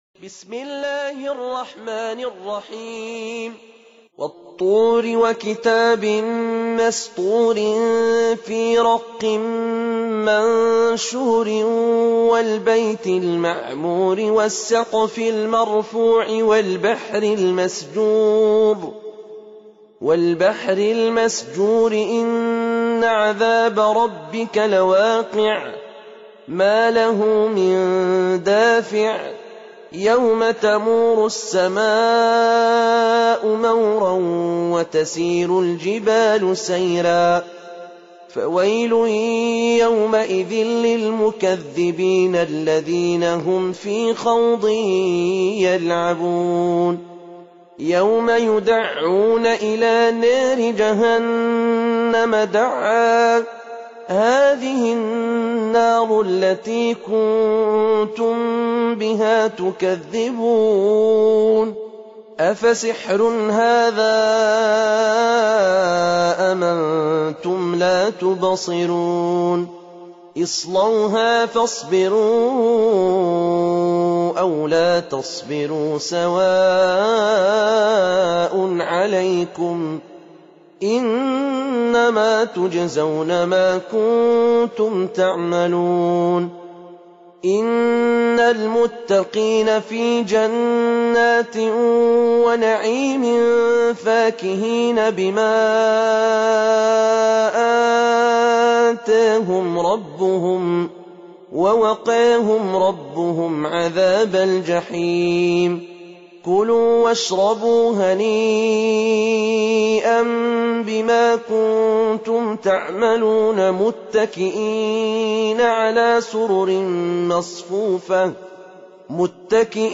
Surah Repeating تكرار السورة Download Surah حمّل السورة Reciting Murattalah Audio for 52. Surah At-T�r سورة الطور N.B *Surah Includes Al-Basmalah Reciters Sequents تتابع التلاوات Reciters Repeats تكرار التلاوات